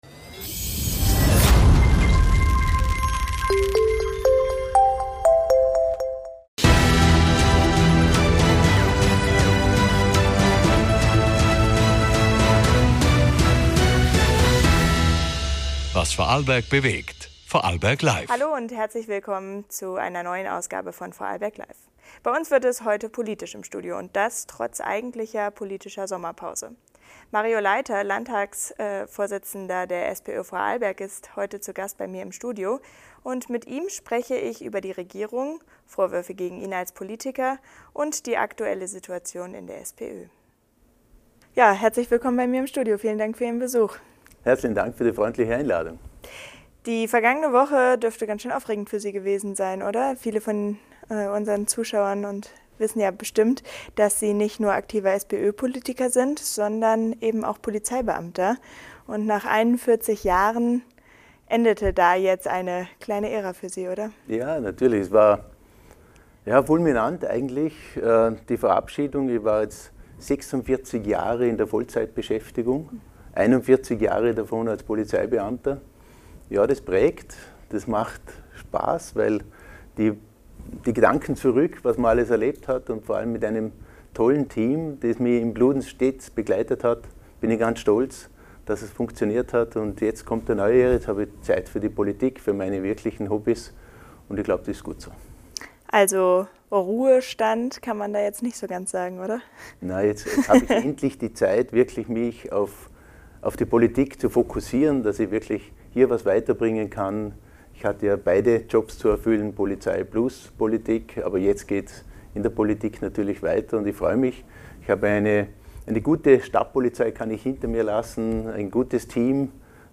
Beschreibung vor 7 Monaten Was läuft schief in Vorarlberg – und was braucht es für mehr soziale Gerechtigkeit? In dieser Folge spricht Mario Leiter, Landesvorsitzender der SPÖ Vorarlberg, mit [Name Moderator:in] über seinen Wechsel von der Polizei in die Politik, interne Konflikte in der SPÖ und seinen Kampf gegen Sozialkürzungen im Land.